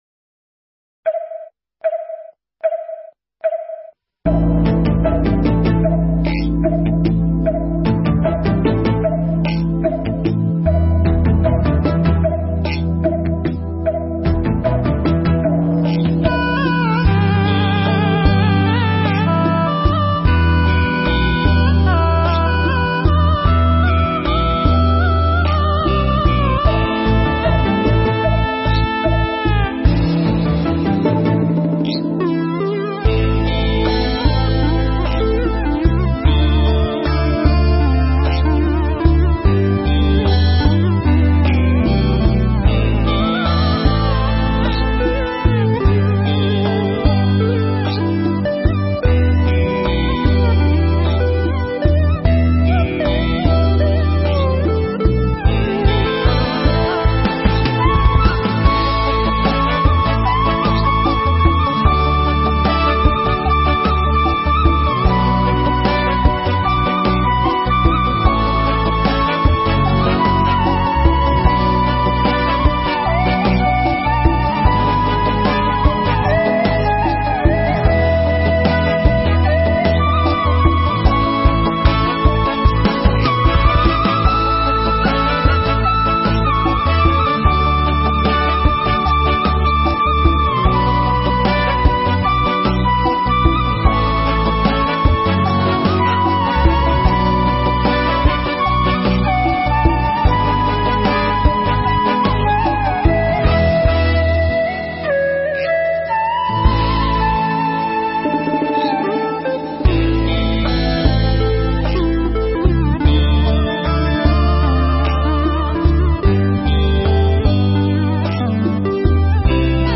Mp3 Thuyết Giảng Nhẫn Một Chút Sóng Yên Gió Lặng